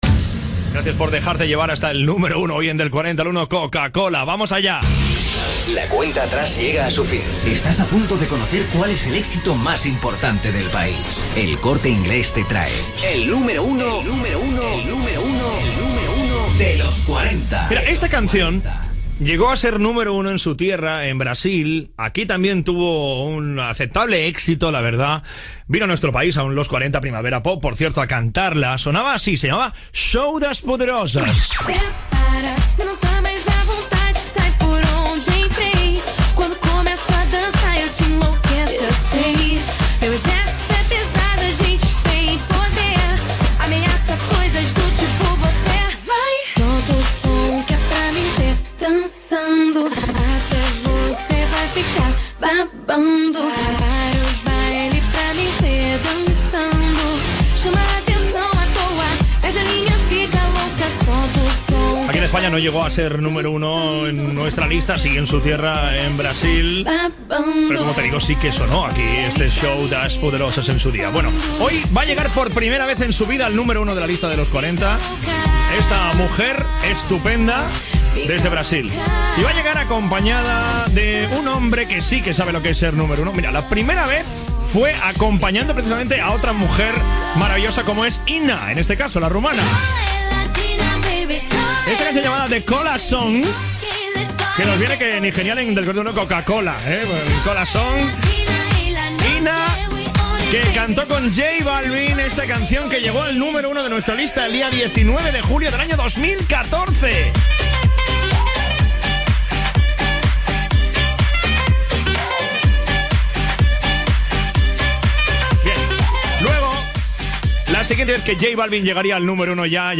Musical
Aguilar, Tony
FM